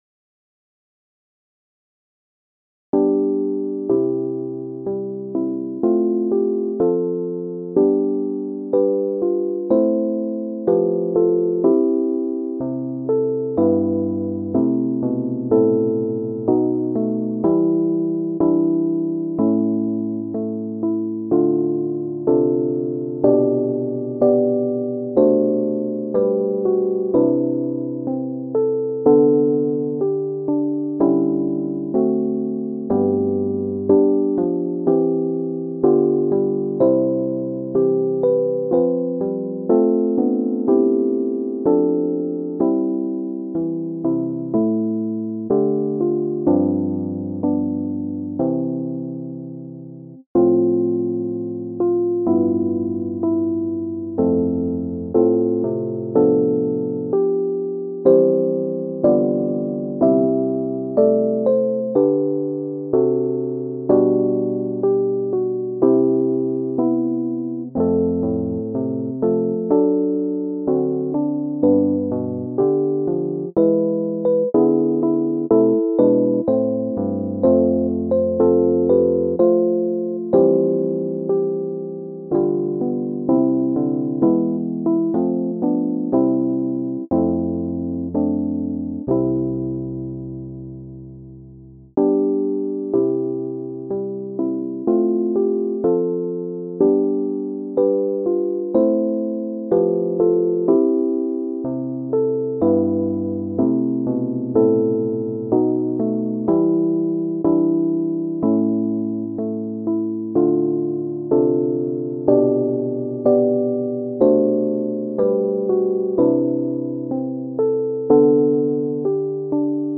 Click the Button to sing the hymn in C, or Pray the prayer music in a New Window